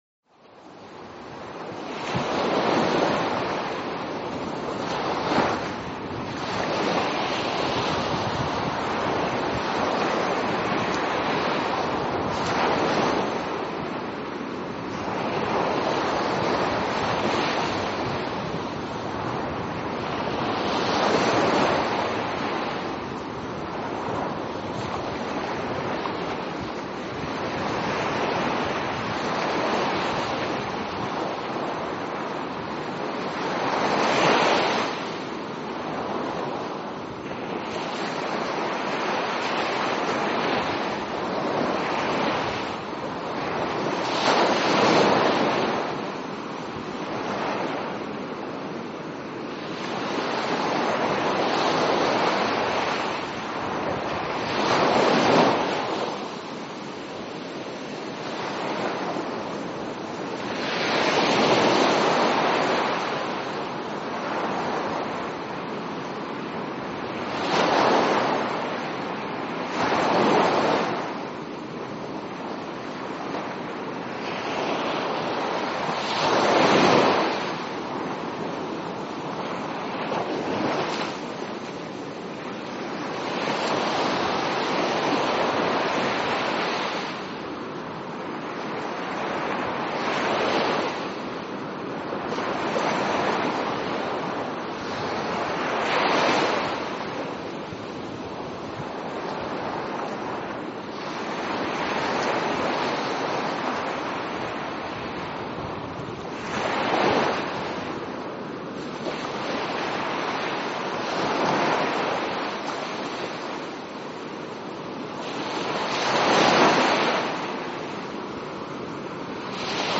Ocean.mp3